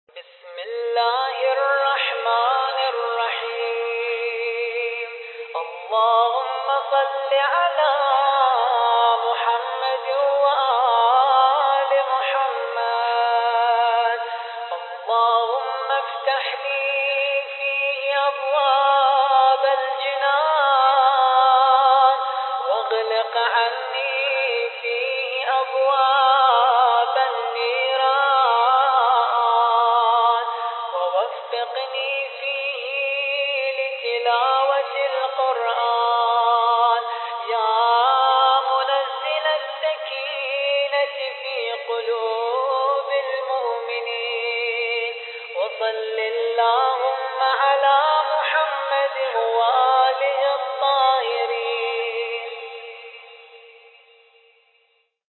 الخطیب: الرادود